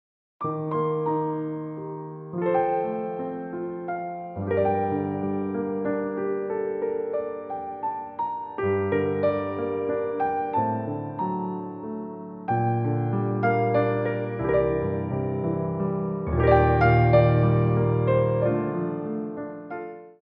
3/4 (8x8)